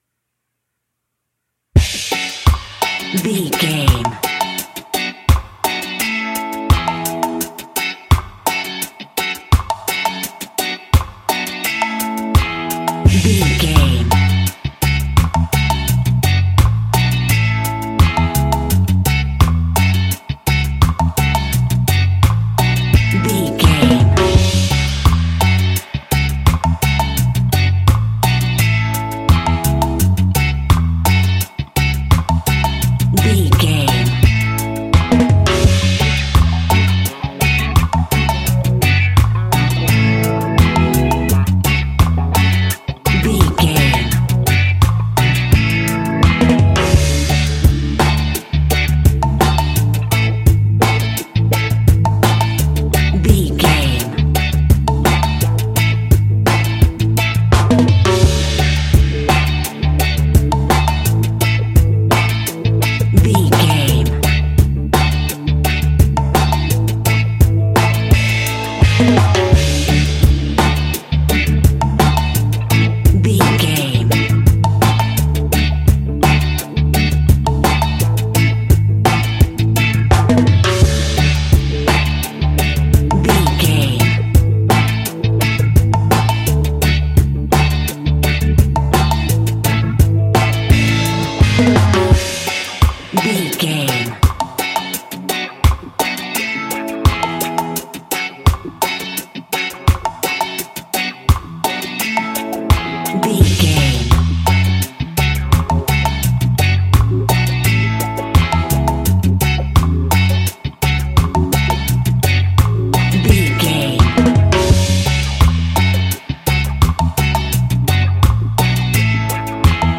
Classic reggae music with that skank bounce reggae feeling.
Uplifting
Aeolian/Minor
F#
dub
laid back
chilled
off beat
drums
skank guitar
hammond organ
percussion
horns